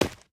gamedata / sounds / material / human / step / asphalt01gr.ogg
asphalt01gr.ogg